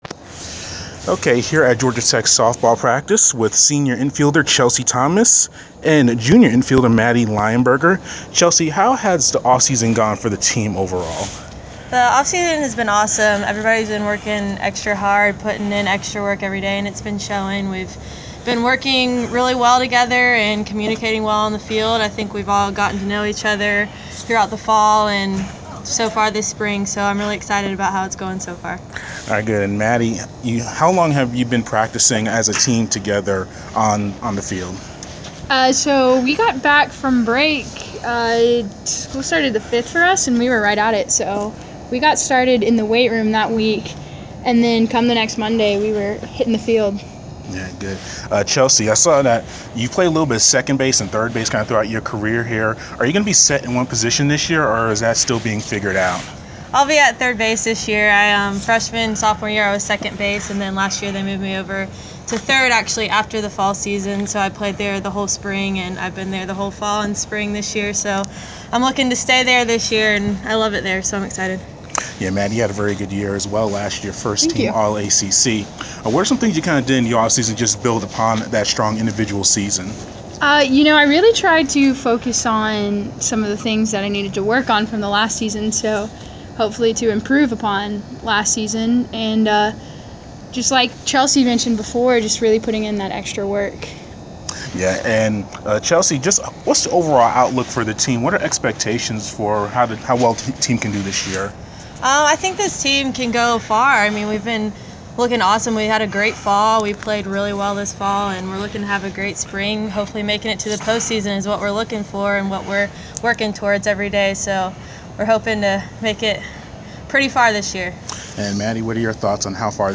gt-sb-pre-season-player-interviews.wav